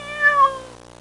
Cat Meow Sound Effect
Download a high-quality cat meow sound effect.
cat-meow.mp3